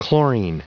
Prononciation du mot chlorine en anglais (fichier audio)
Prononciation du mot : chlorine